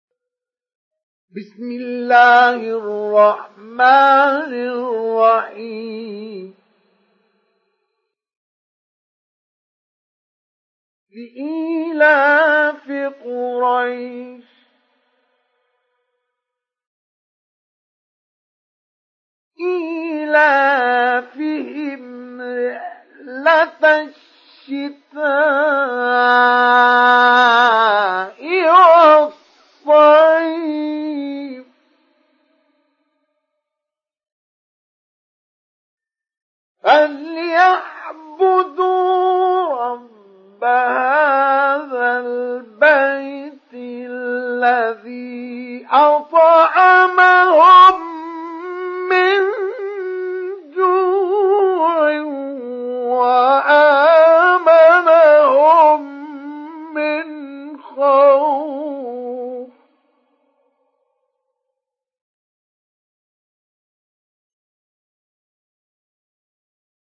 سُورَةُ قُرَيۡشٍ بصوت الشيخ مصطفى اسماعيل